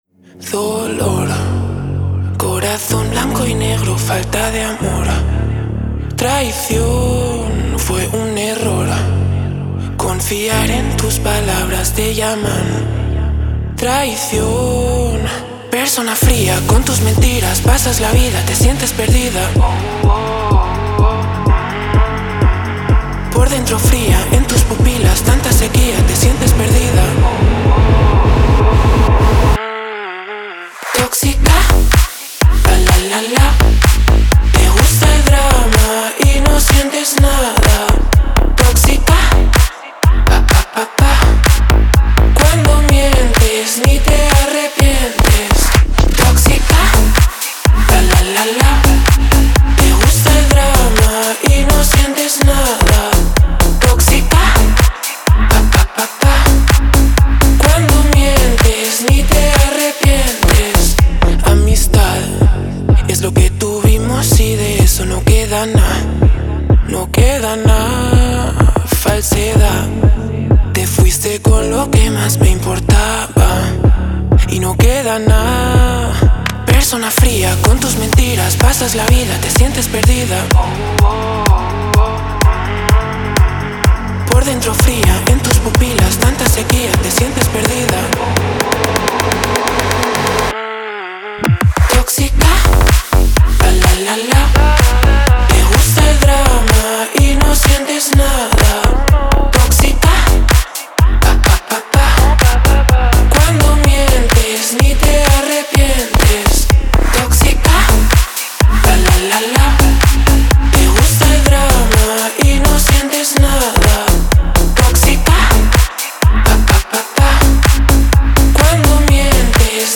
это зажигательная танцевальная композиция в жанре EDM